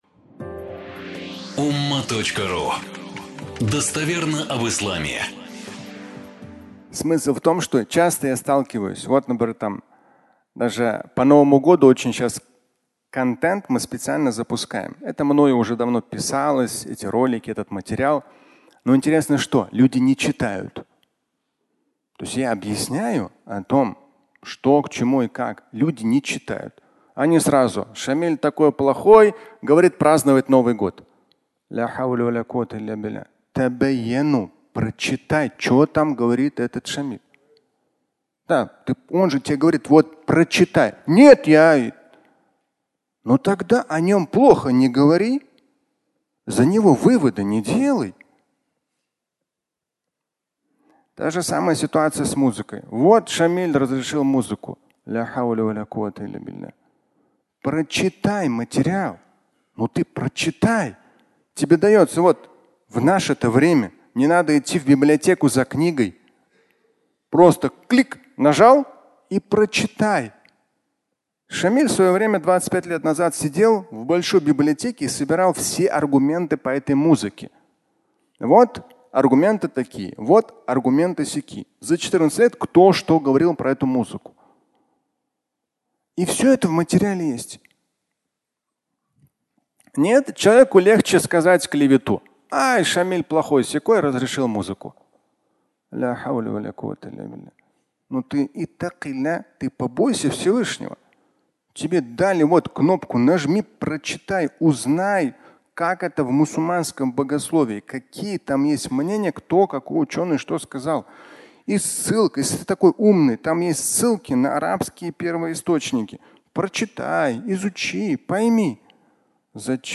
Вывод на клевете (аудиолекция)